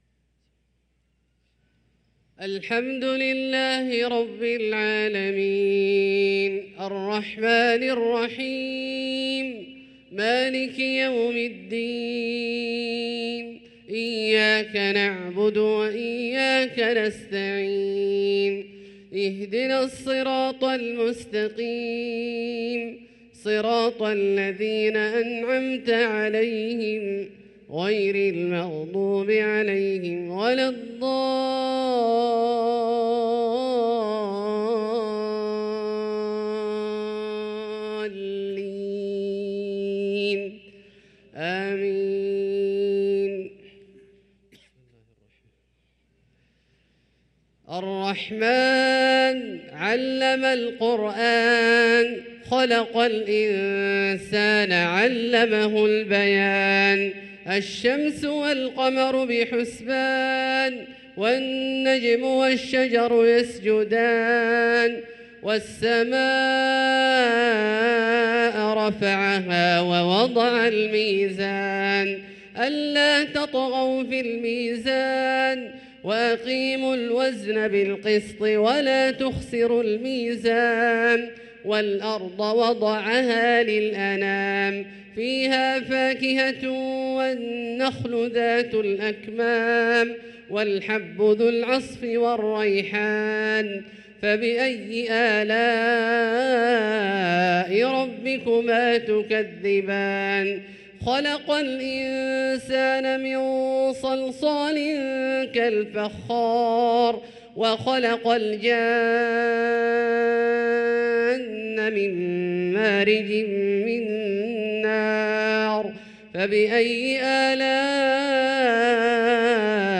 صلاة الفجر للقارئ عبدالله الجهني 3 صفر 1445 هـ